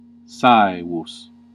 Ääntäminen
IPA : /ˈsævɪdʒ/